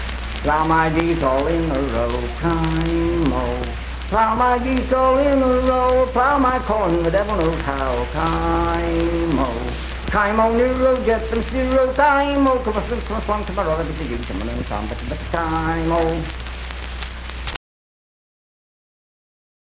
Unaccompanied vocal music performance
Dance, Game, and Party Songs
Voice (sung)